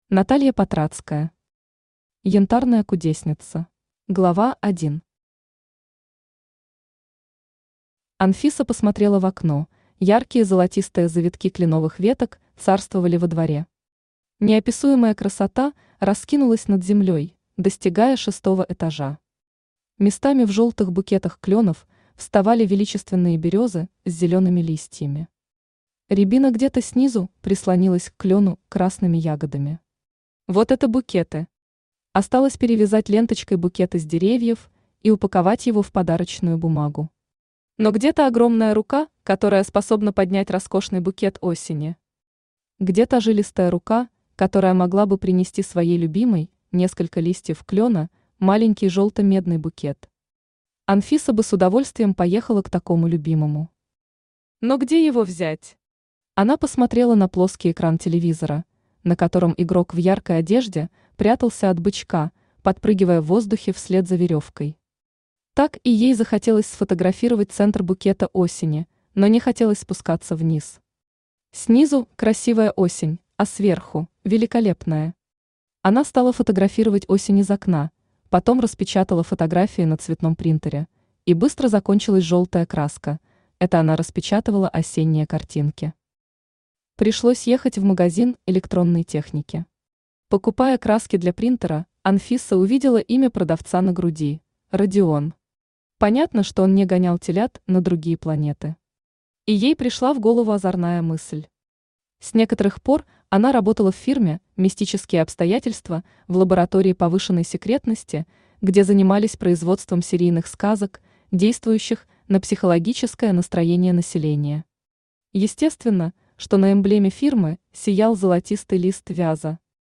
Аудиокнига Янтарная кудесница | Библиотека аудиокниг
Aудиокнига Янтарная кудесница Автор Наталья Владимировна Патрацкая Читает аудиокнигу Авточтец ЛитРес.